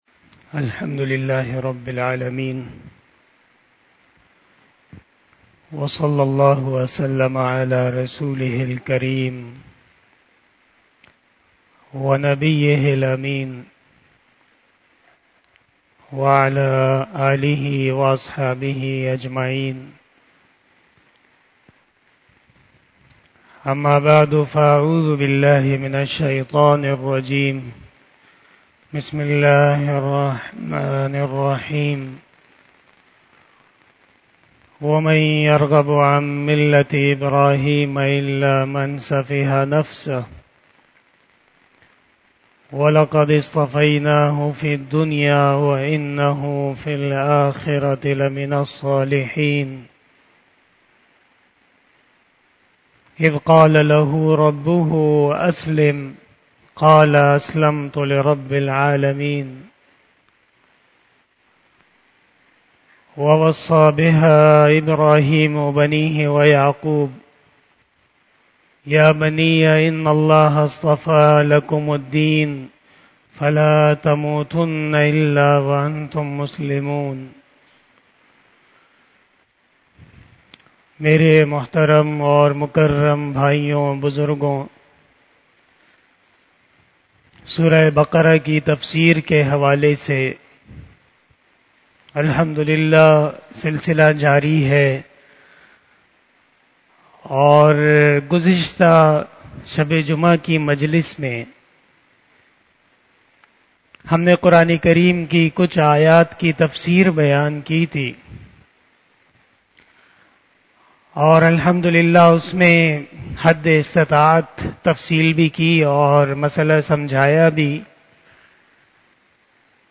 بیان شب جمعۃ المبارک